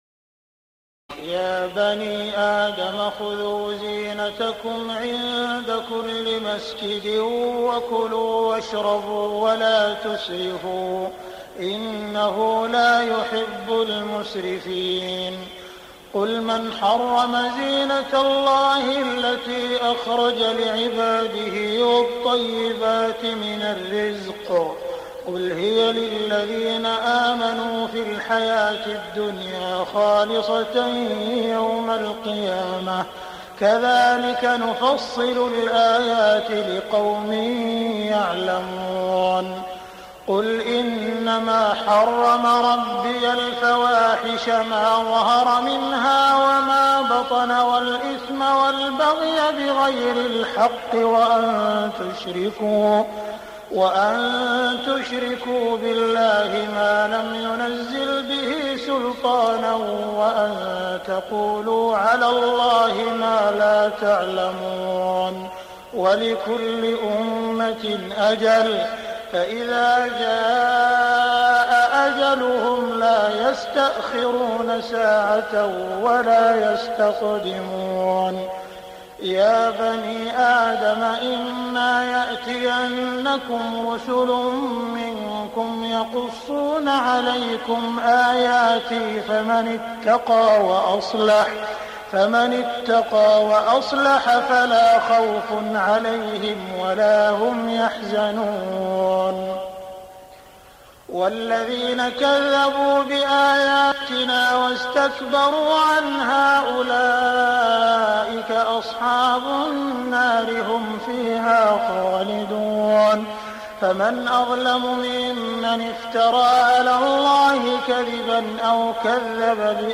تهجد ليلة 28 رمضان 1418هـ من سورة الأعراف (31-93) Tahajjud 28 st night Ramadan 1418H from Surah Al-A’raf > تراويح الحرم المكي عام 1418 🕋 > التراويح - تلاوات الحرمين